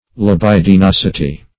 Search Result for " libidinosity" : The Collaborative International Dictionary of English v.0.48: Libidinosity \Li*bid`i*nos"i*ty\ (-n[o^]s"[i^]*t[y^]), n. The state or quality of being libidinous; libidinousness.